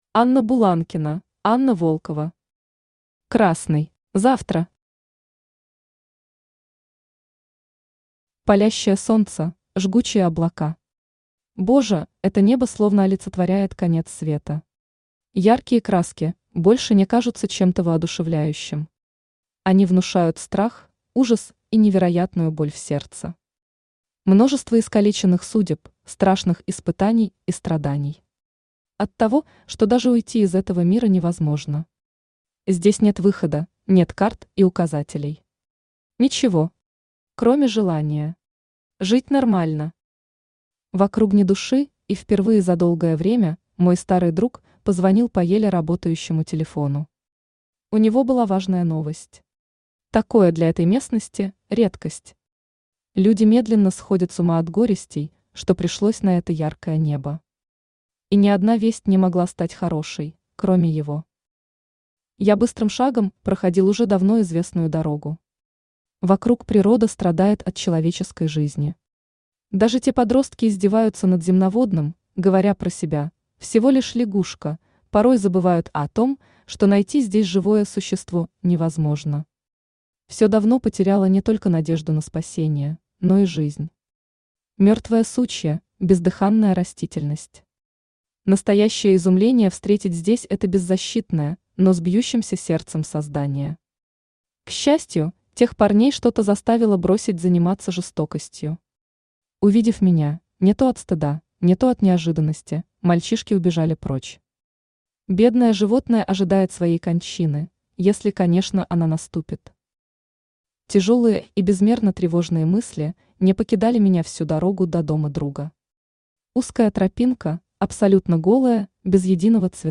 Аудиокнига Красный | Библиотека аудиокниг
Aудиокнига Красный Автор Анна Сергеевна Буланкина Читает аудиокнигу Авточтец ЛитРес.